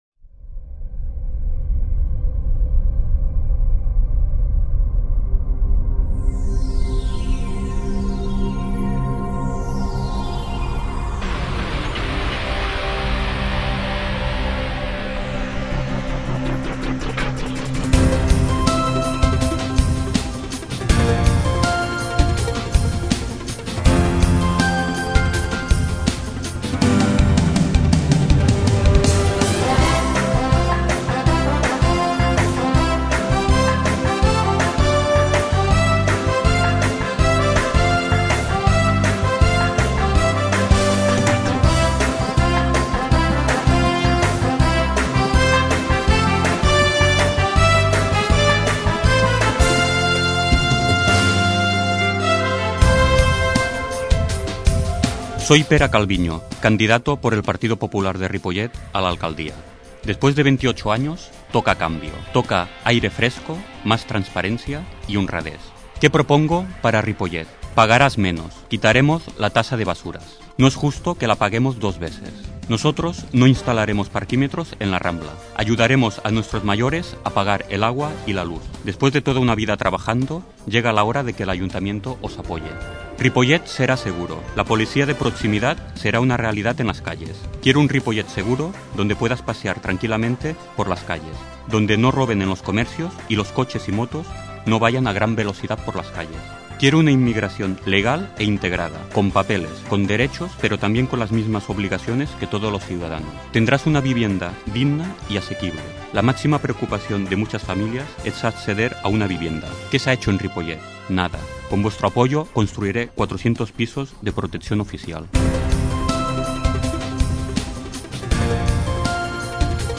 Descarregueu i escolteu els espais radiofònics enregistrats pels partits polítics de Ripollet a l'emissora municipal Fitxers relacionats ripollet-politica-eleccions-espai-electoral-municipals-2007-PP-270507.mp3 audio/mpeg | 3.3 MB